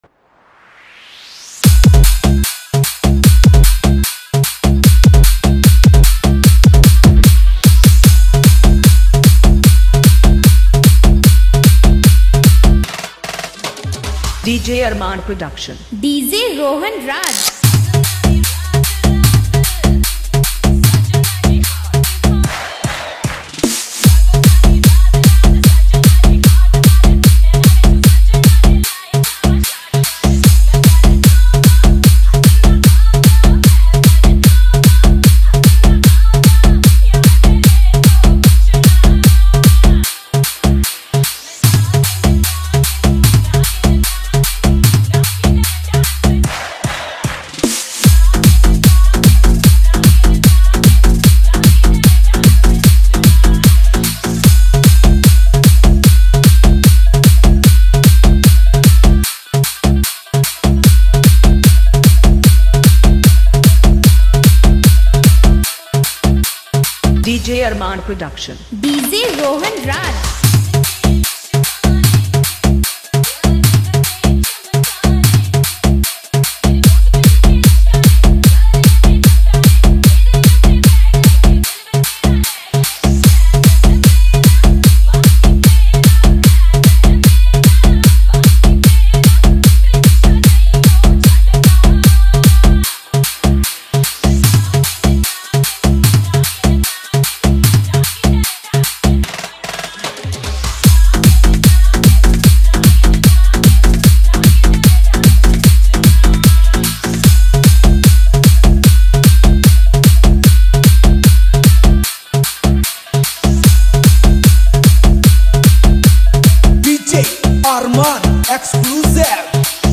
Category : Hindi Remix Song